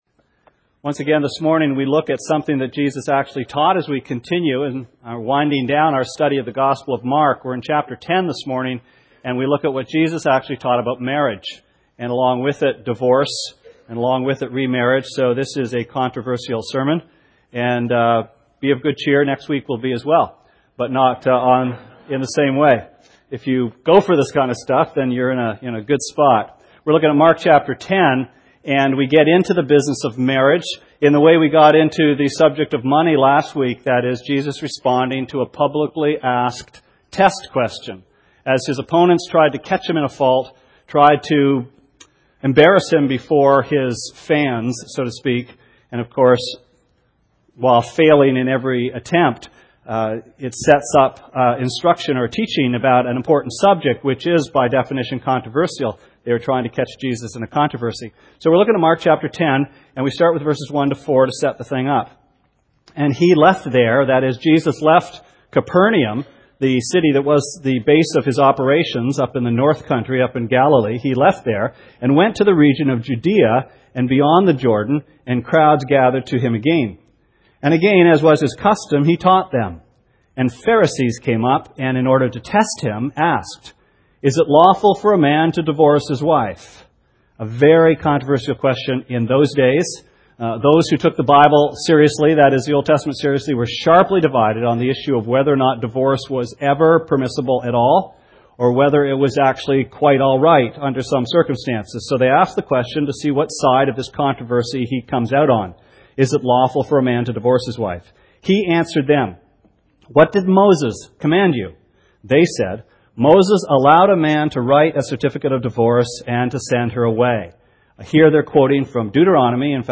Sermon Archives June 14